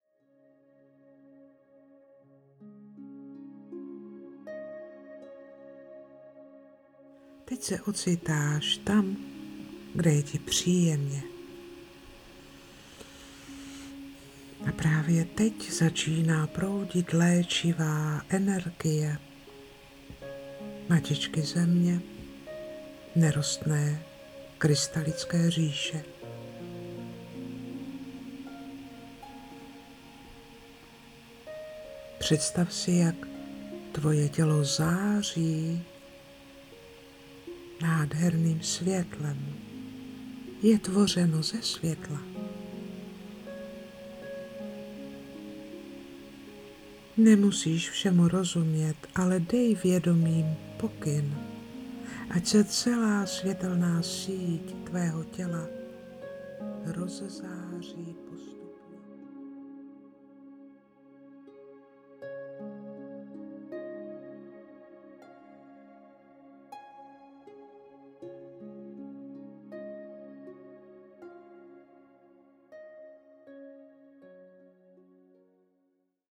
2. den – 2. meditace